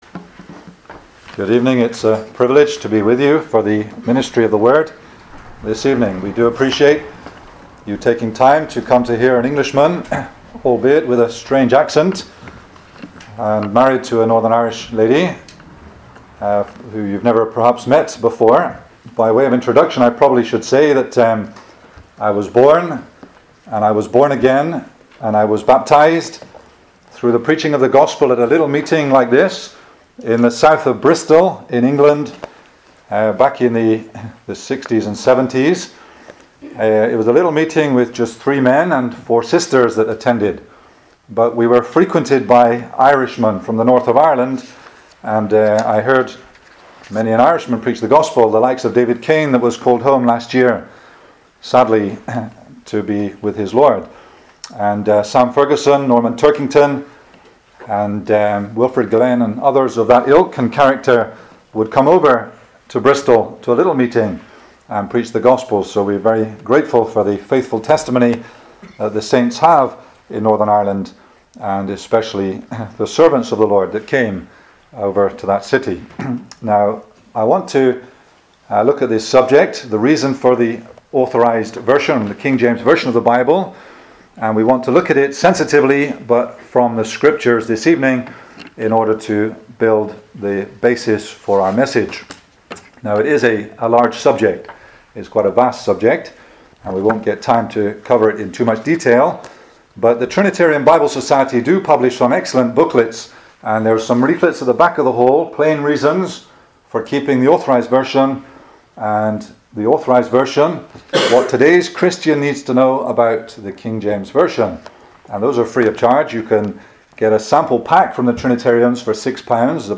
Psalms 34:1-22 Service Type: Ministry